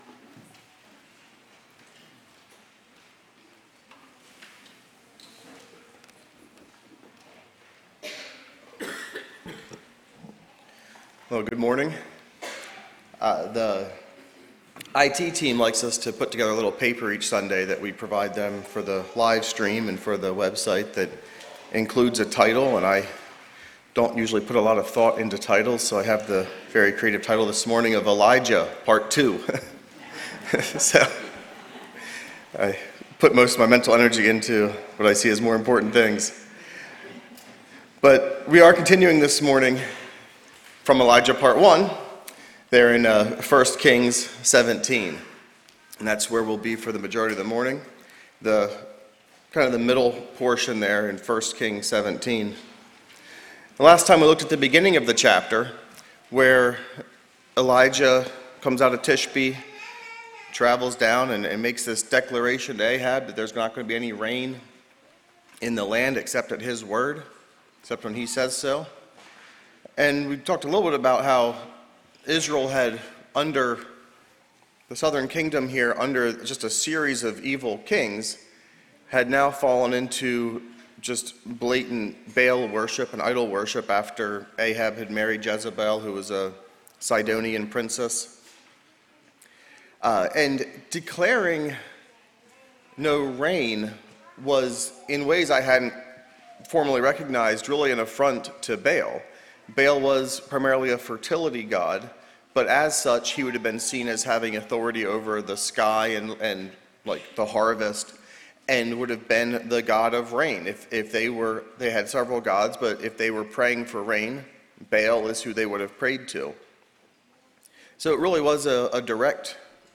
A message from the series "Sunday Morning - 10:30."